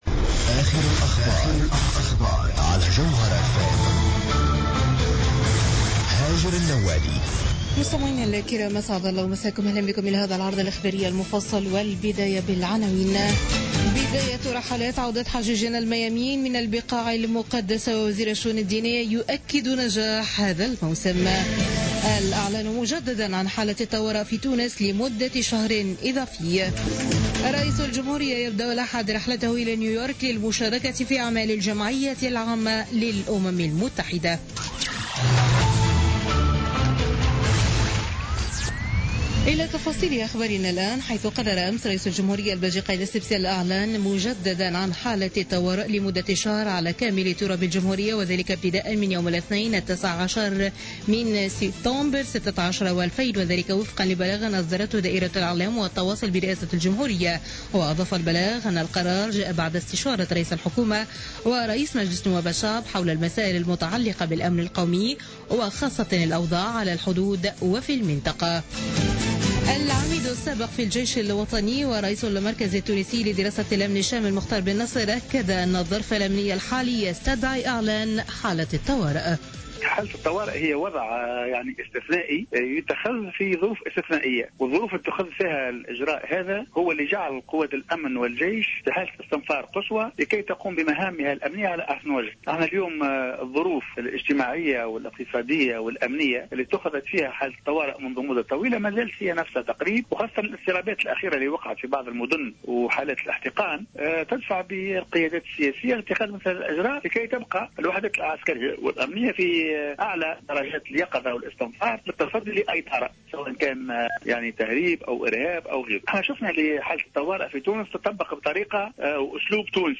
نشرة أخبار منتصف الليل ليوم الأحد 18 سبتمبر 2016